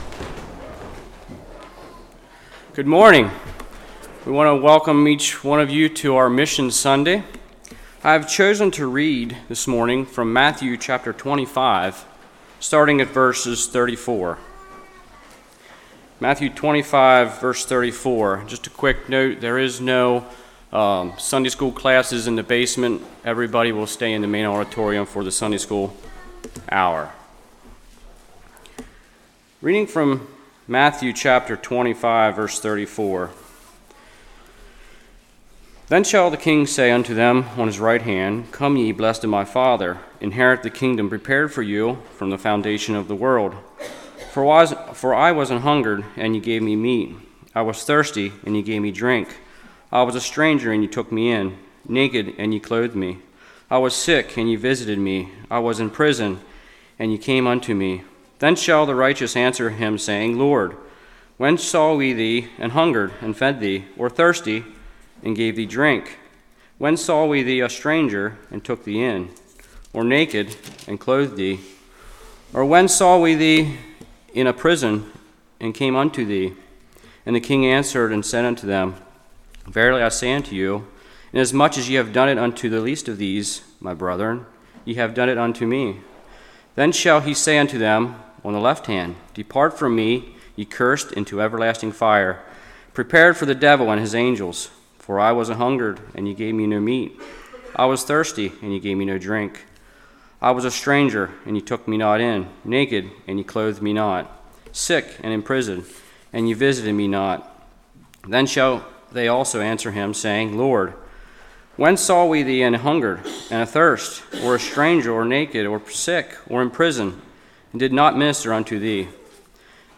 Testimonies from Mission Trips:
Service Type: Sunday School